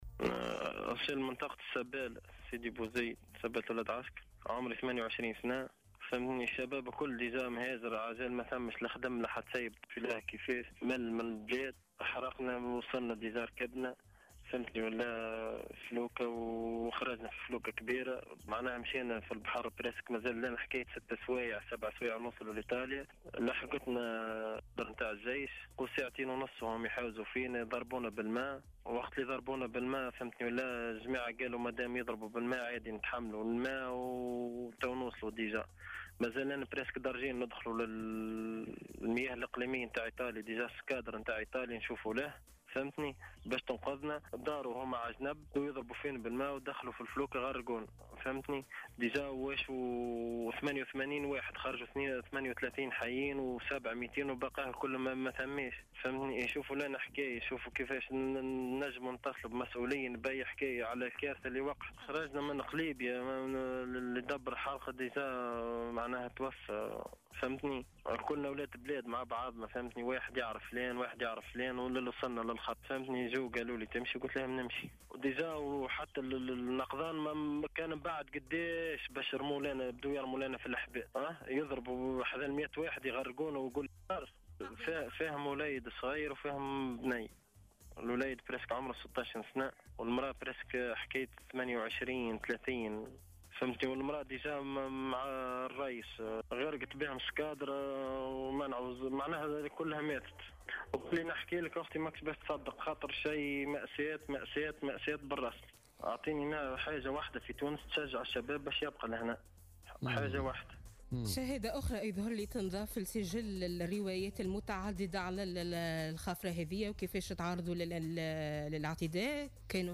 أحد الناجين من حادث اصطدام مركب حراقة بخافرة للجيش يروي التفاصيل